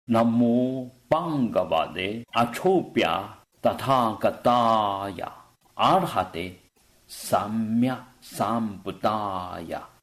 唱誦